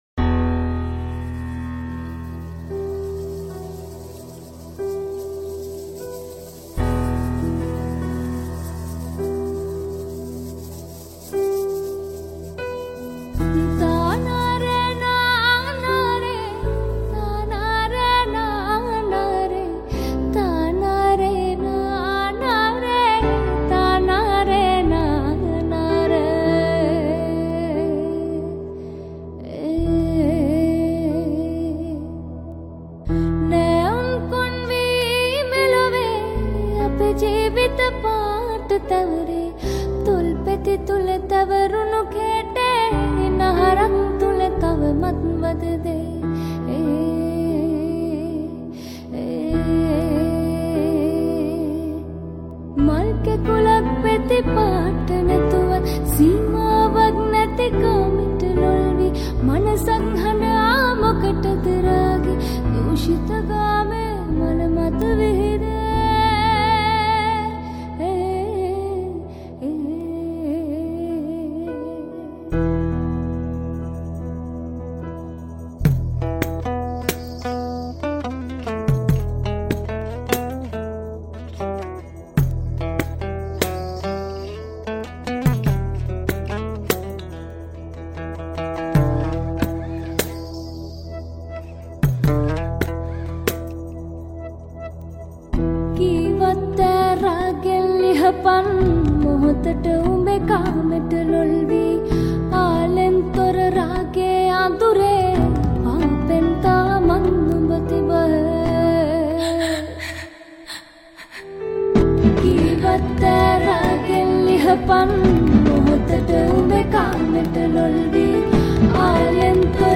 Guitar, Piano